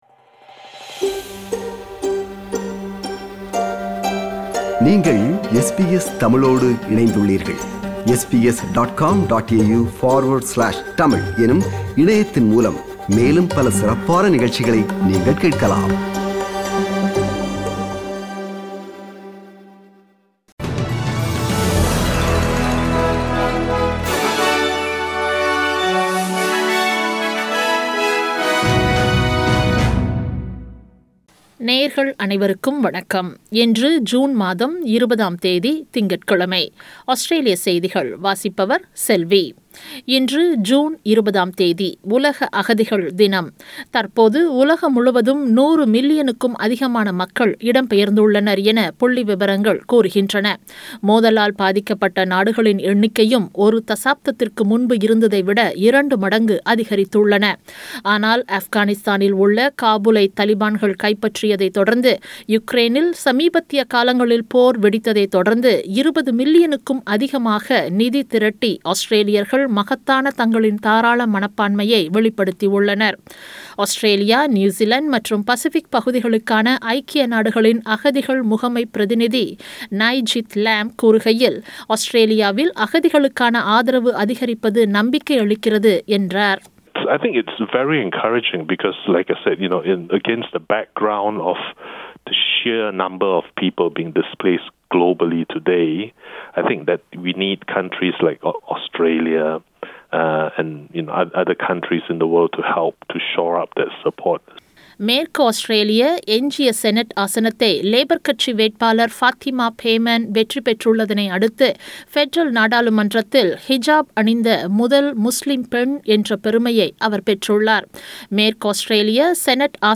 Australian news bulletin for Monday 20 June 2022.